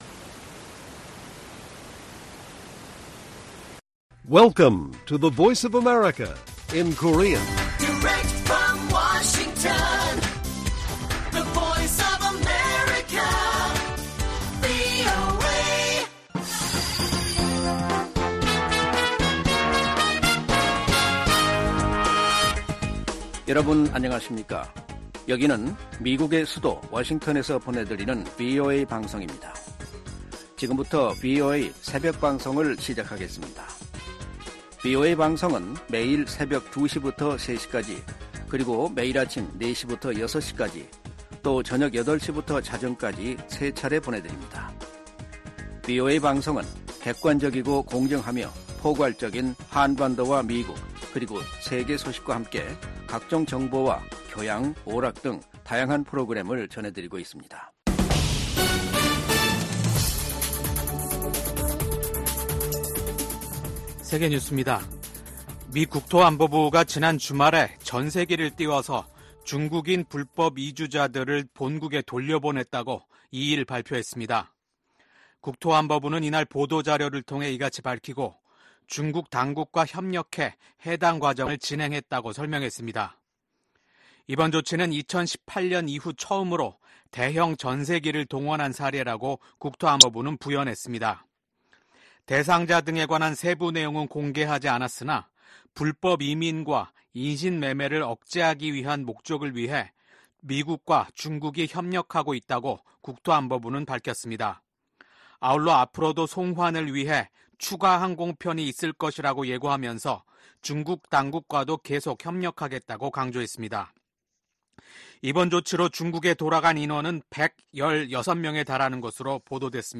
VOA 한국어 '출발 뉴스 쇼', 2024년 7월 4일 방송입니다. 미국 국방부가 북한의 최근 탄도미사일 발사를 비판하며 계속 심각하게 받아들일 것이라고 밝혔습니다. 미국의 미사일 전문가들은 북한이 아직 초대형 탄두 미사일이나 다탄두 미사일 역량을 보유하지 못한 것으로 진단했습니다. 유엔 제재하에 있는 북한 선박이 중국 항구에 입항했습니다.